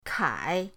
kai3.mp3